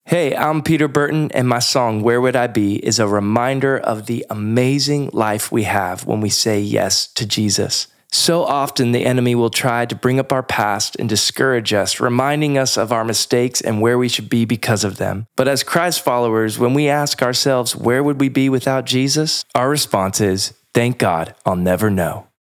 singer-songwriter and worship leader
energetic and catchy sound